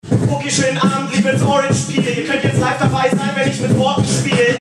Und bei uns in Frankfurt, da gibt’s auch einen Songslam.
Du hörst: Ob du jetzt rappst oder ein zartes Liebeslied mit der Gitarre singst, spielt bei einem Song Slam keine so große Rolle.
Songslam-Frankfurt.mp3